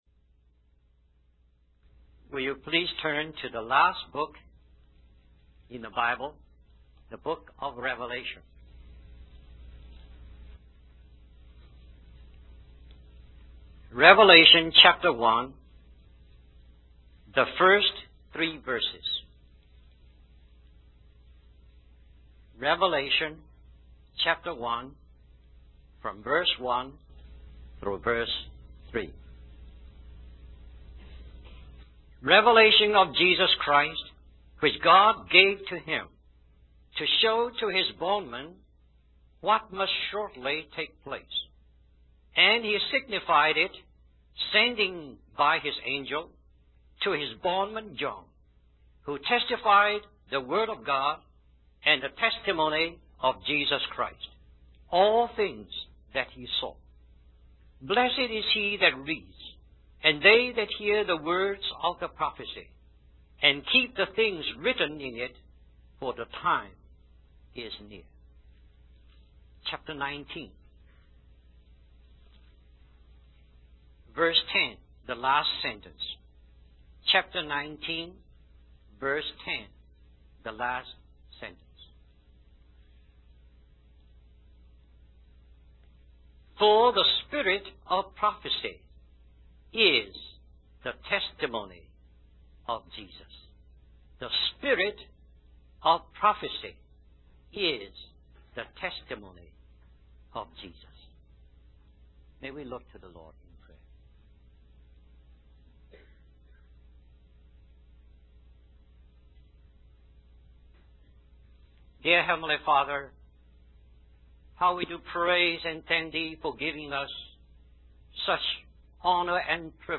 In this sermon, the speaker discusses how throughout history, whenever God entrusts his revelation to man, man quickly loses it.